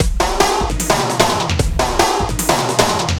CRATE HF DRM 1.wav